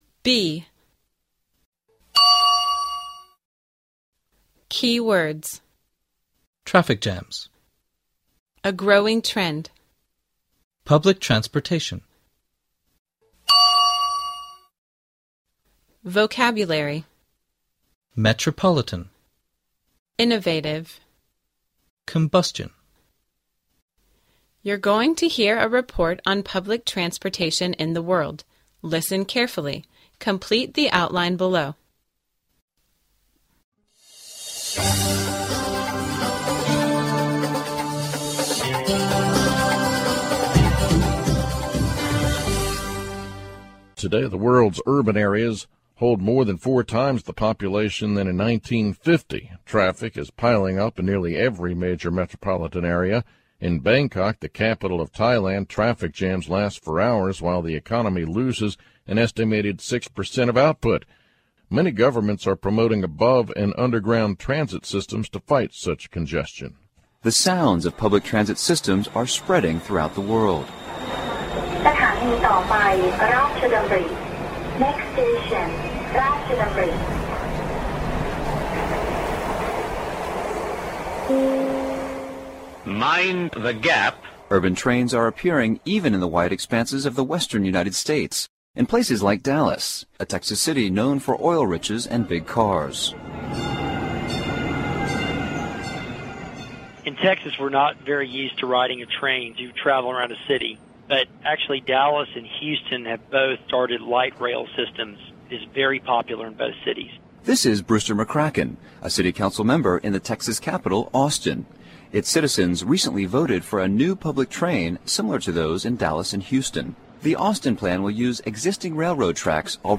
You're going to hear a report on public transportation in the world, listen carefully, complete the outline below.